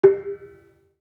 Kenong-dampend-G3-f.wav